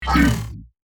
Stealth, Spy, Game Menu, Ui Tab Switch Sound Effect Download | Gfx Sounds
Stealth-spy-game-menu-ui-tab-switch.mp3